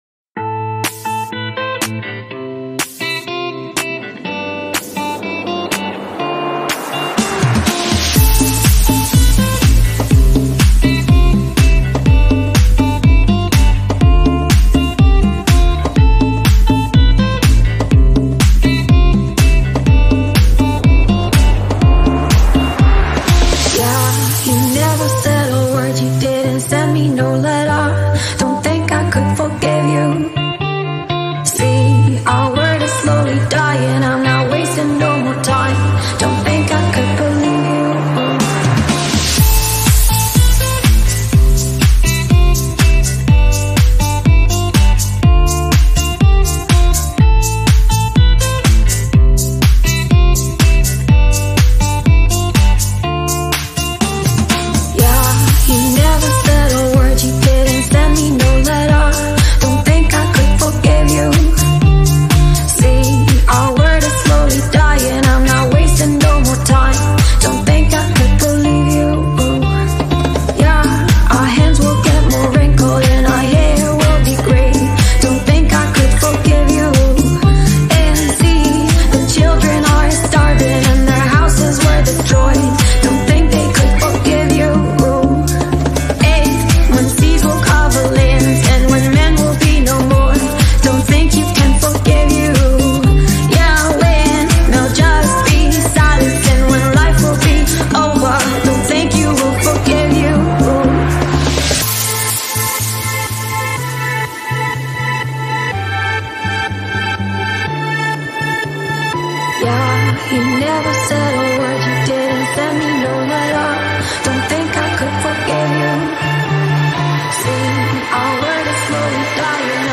Carpeta: Baile internacional mp3